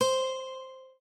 lute_c.ogg